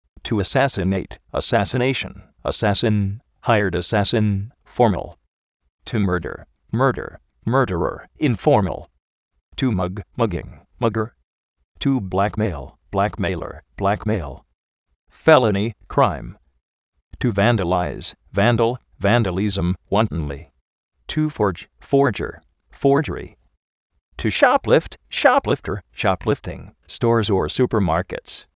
tu asásinet, asasinéishn, asásin, jáiard asásin (fó:mal)
to mág, máguing, máguer